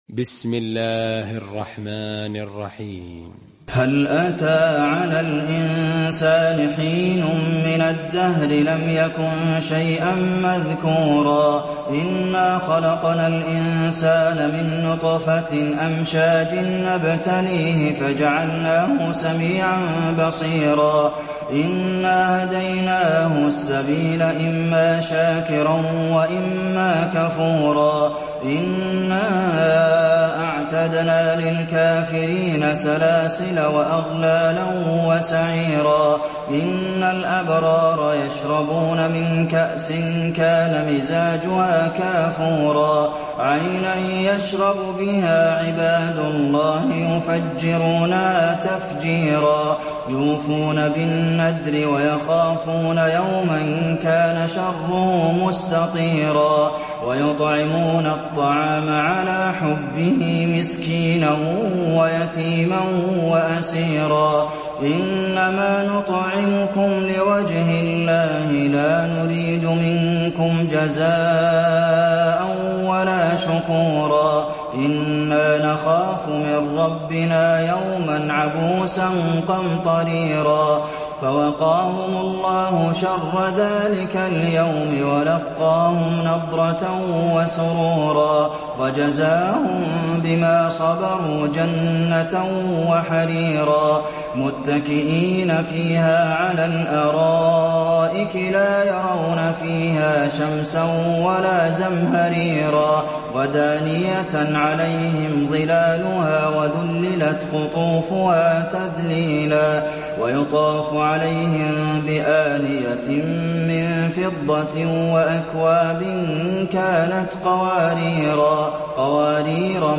المكان: المسجد النبوي الإنسان